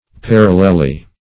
Search Result for " parallelly" : The Collaborative International Dictionary of English v.0.48: Parallelly \Par"al*lel*ly\, adv. In a parallel manner; with parallelism.